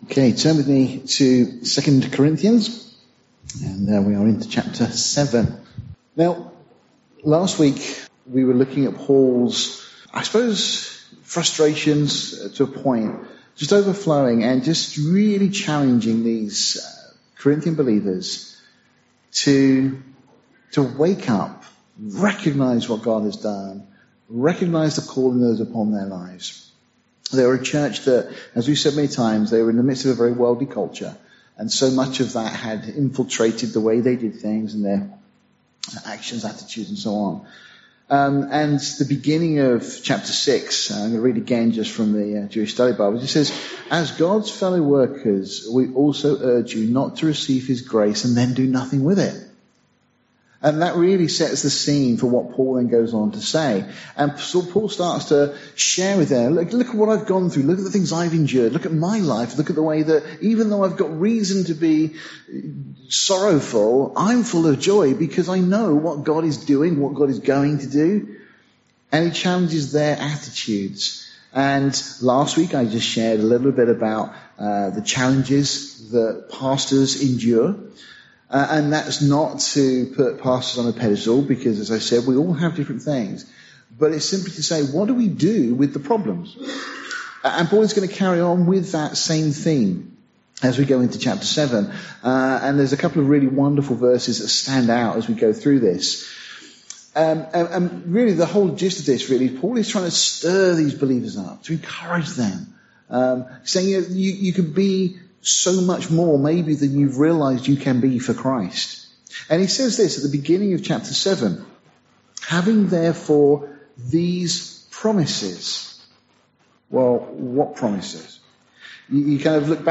Series: Sunday morning studies Tagged with verse by verse